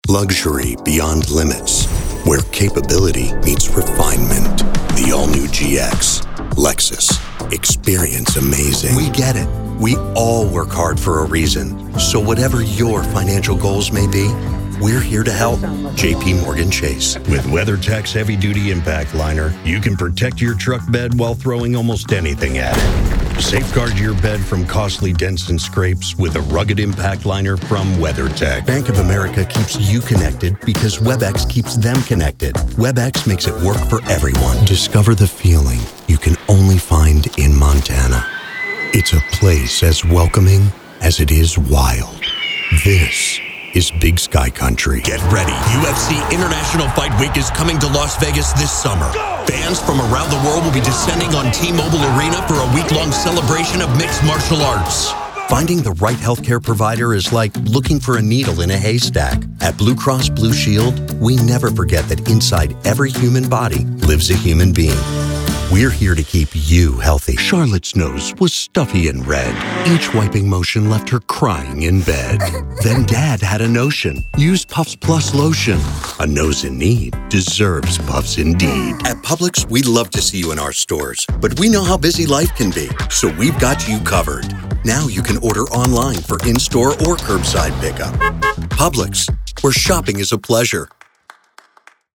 Demos
Voice Age
Young Adult
Middle Aged
Commercial